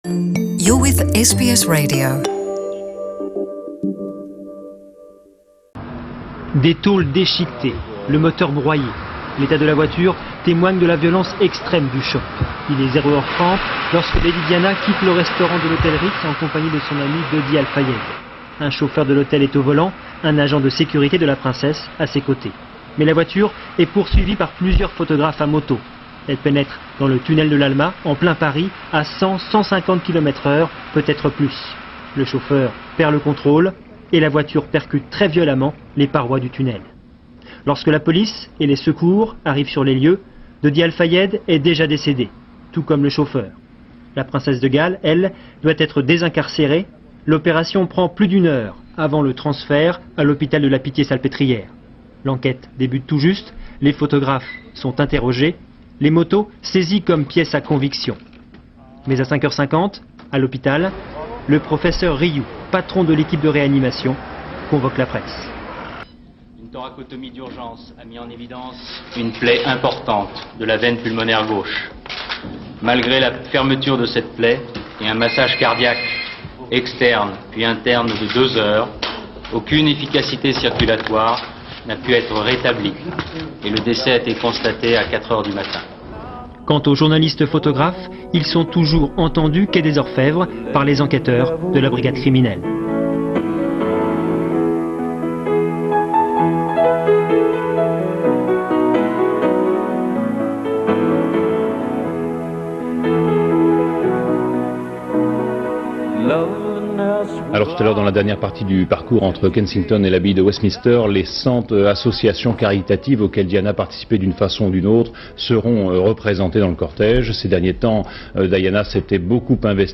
Il y a 22 ans.. La Mort de la Princesse Diana - Retour sur cet evenement avec les archives de l'INA